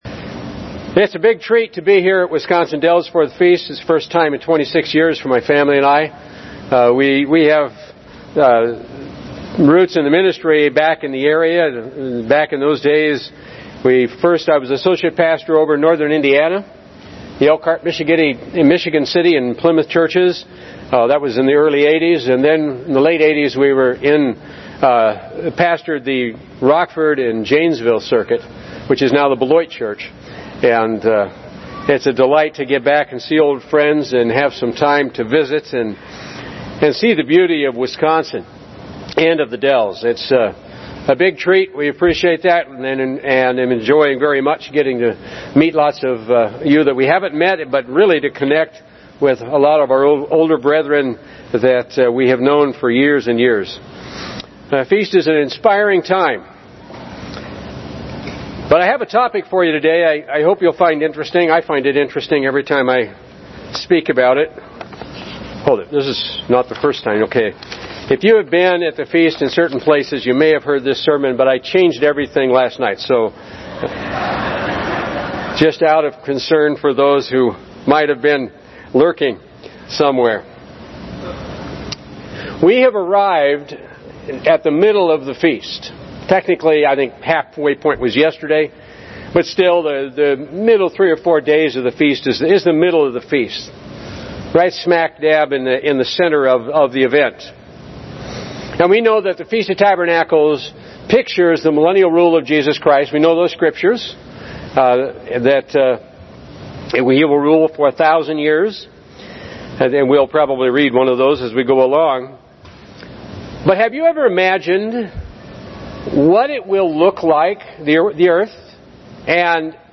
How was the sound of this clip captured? This sermon was given at the Wisconsin Dells, Wisconsin 2017 Feast site.